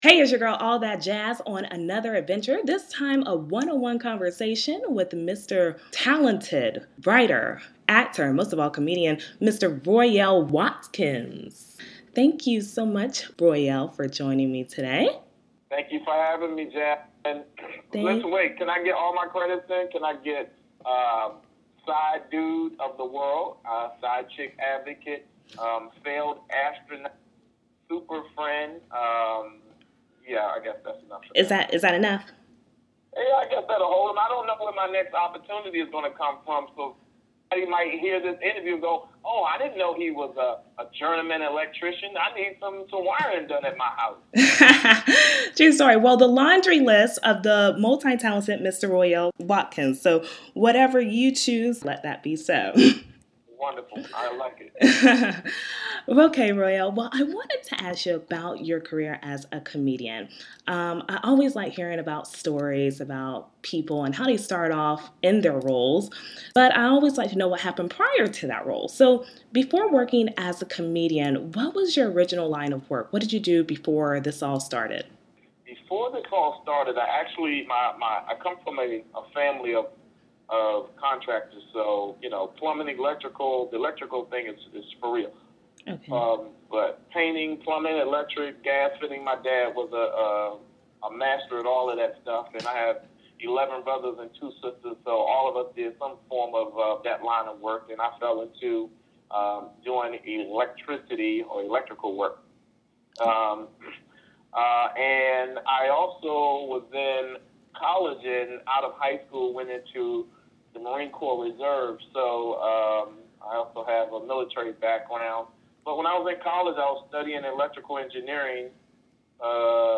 A One on One Conversation with Royale Watkins
Listen to my latest adventure as I have a one on one conversation with the talented writer, actor, and comedian Mr. Royale Watkins! Royale gives his story about his career as a stand up comedian and tells of his latest venture as cofounder of the Mixtape Comedy Show where Anthony Anderson and himself host the show every 3rd Sunday in New York City at the Gotham Comedy Club.
royale-interview123.mp3